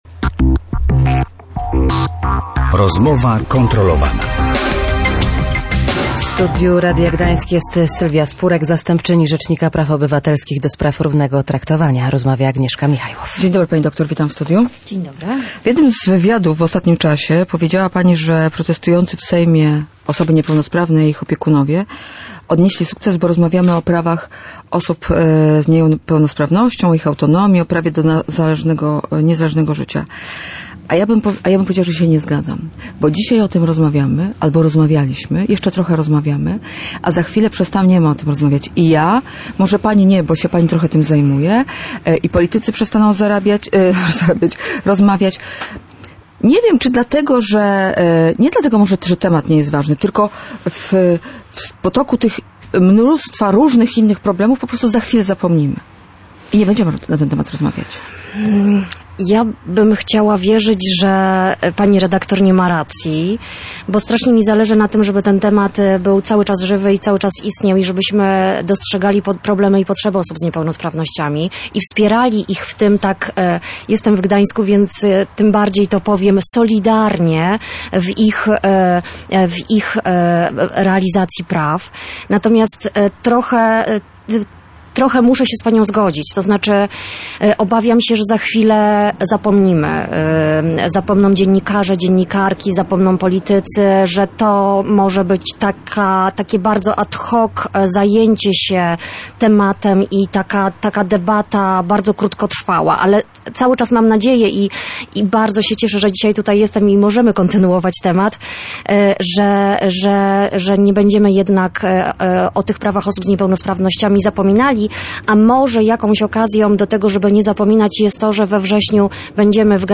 Protestujący w sejmie, osoby niepełnosprawne i ich opiekunowie odnieśli sukces, ponieważ rozmawiamy o niepełnosprawności, o ich autonomii i prawach do niezależnego życia – mówiła zastępczyni Rzecznika Praw Obywatelskich. Wyraziła jednak obawy, że temat już niedługo może zniknąć z przestrzeni medialnej.